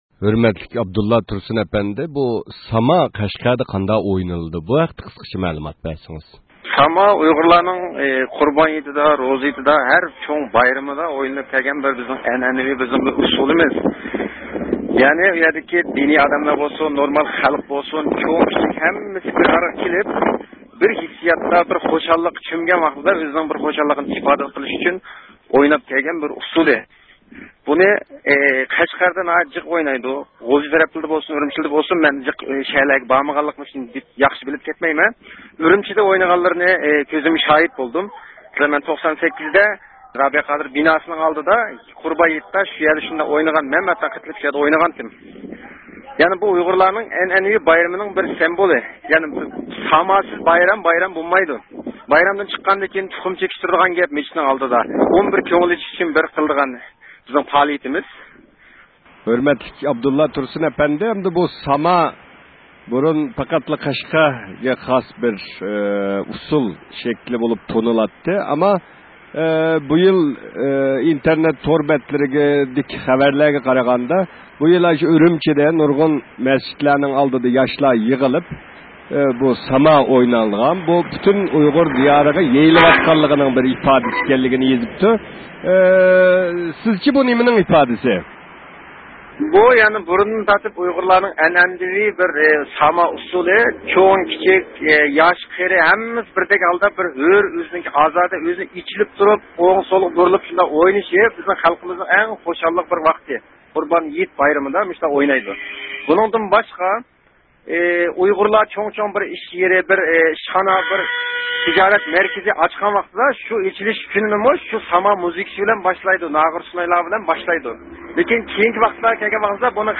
بۇنىڭغا ئوخشاش بىر يۈرۈش سۇئاللارغا جاۋاپ تېپىش ئۈچۈن ئۇيغۇرلار بىلەن سۆھبەت ئېلىپ باردۇق.